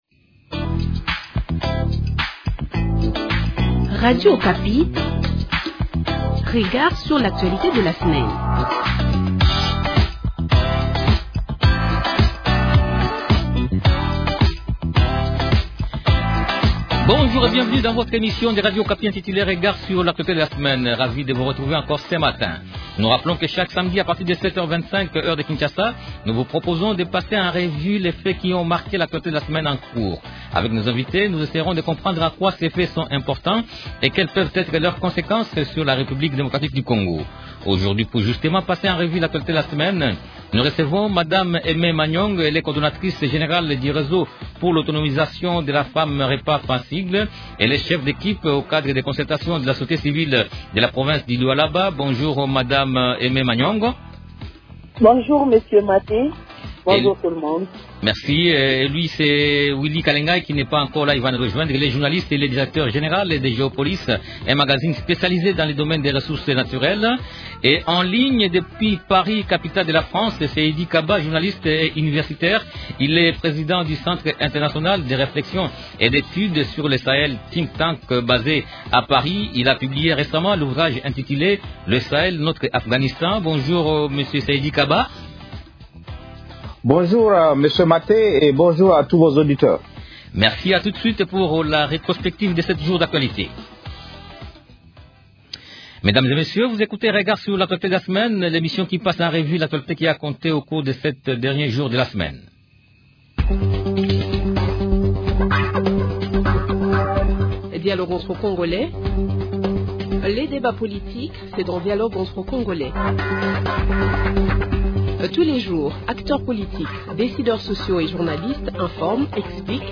-Et en ligne depuis Paris, capitale de la France